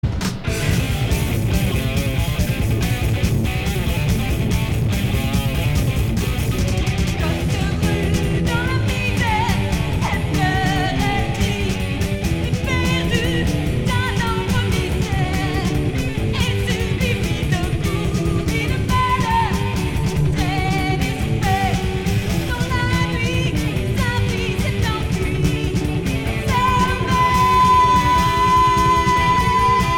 Hard heavy Unique 45t